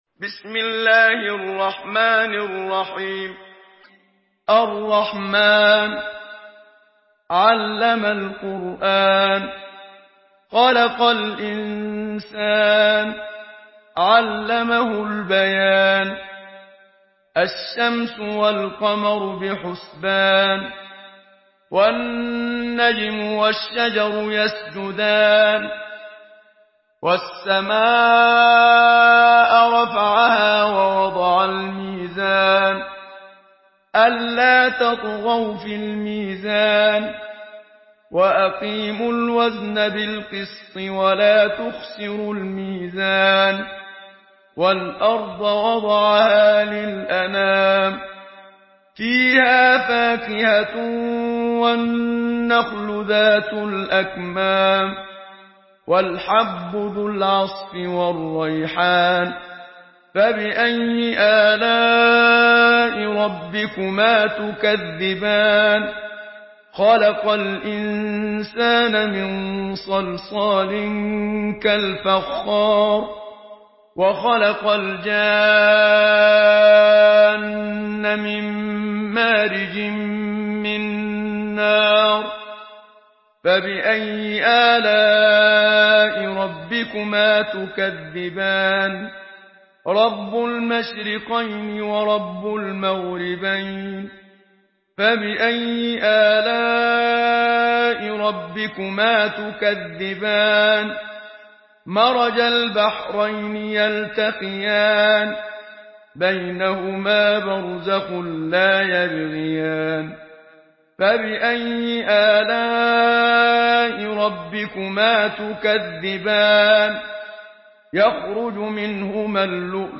Surah الرحمن MP3 by محمد صديق المنشاوي in حفص عن عاصم narration.